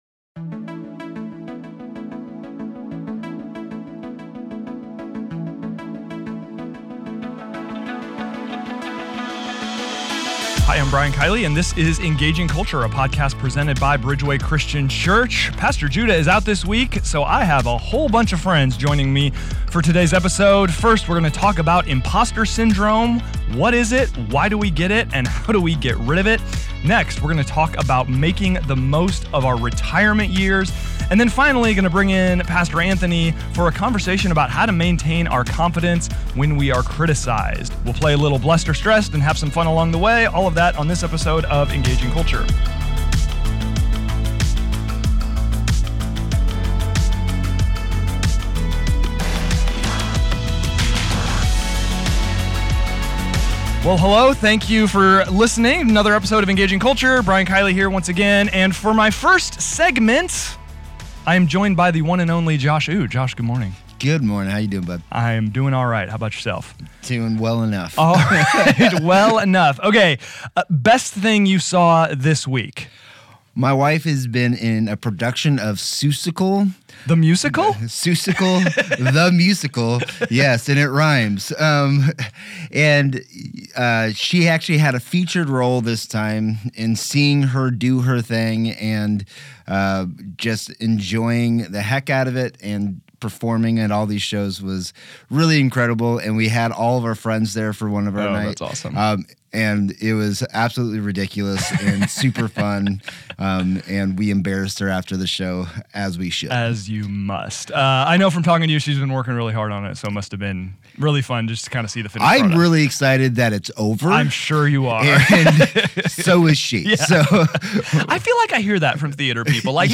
welcomes several guests to the studio.